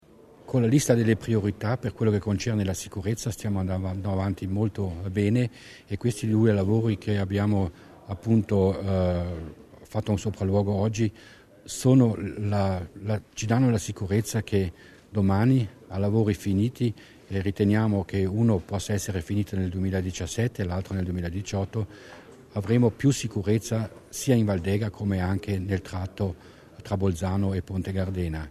L'Assessore Mussner ricorda gli investimenti per la qualità delle strade altoatesine